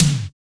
NASTY TOM.wav